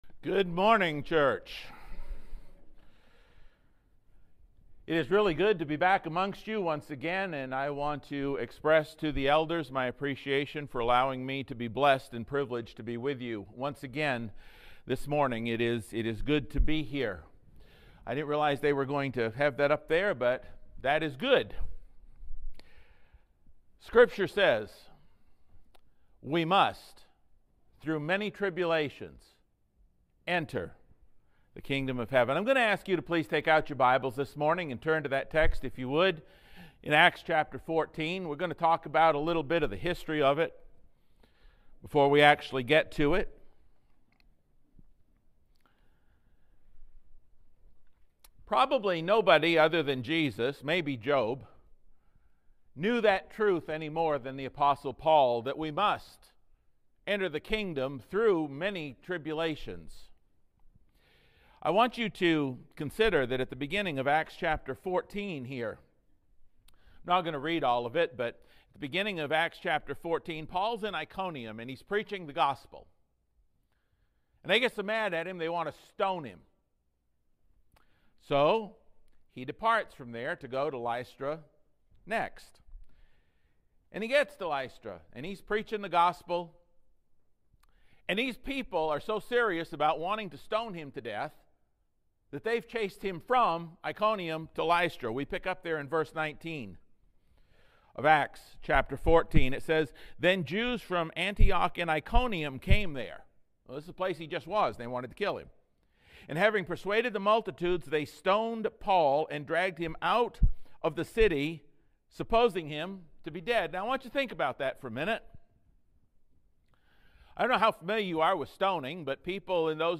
Scripture Reading